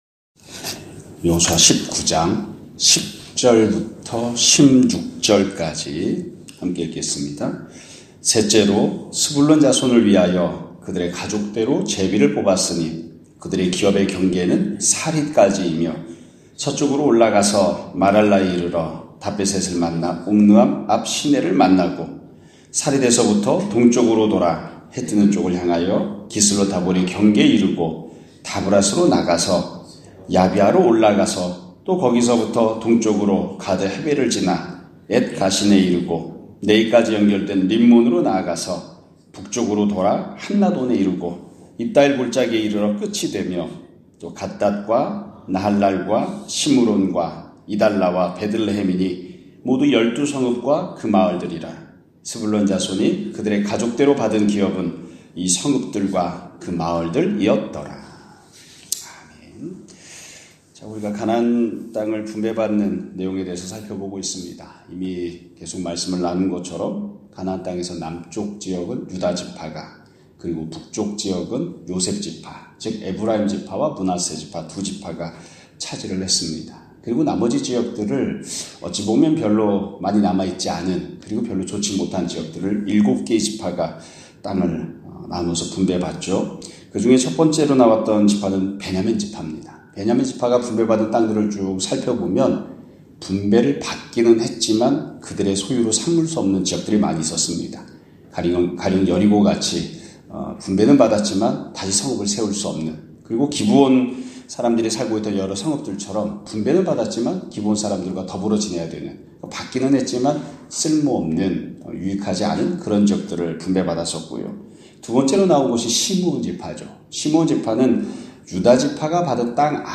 2025년 1월 6일(월 요일) <아침예배> 설교입니다.